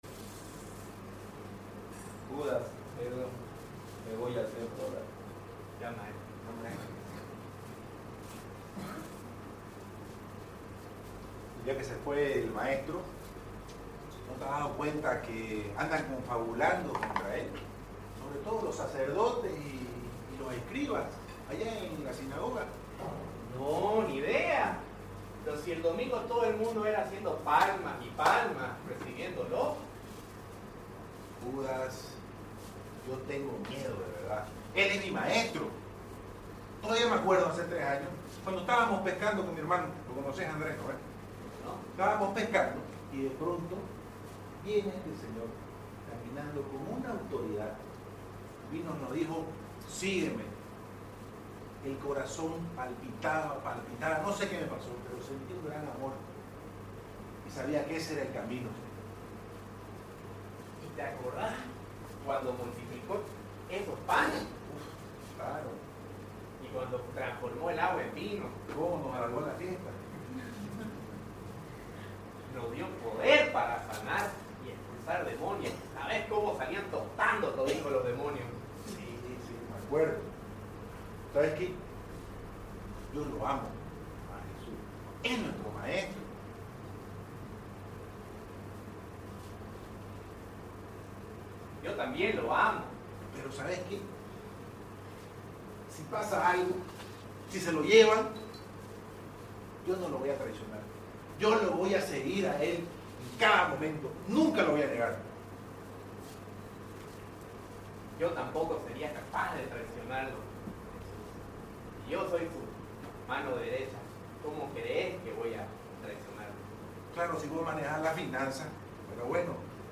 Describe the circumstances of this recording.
Retiro de Semana Santa 2018 organizado por los Guardianas y Defensores de la Fe con el apoyo de los hermanos Discípulos de Jesús de San Juan Bautista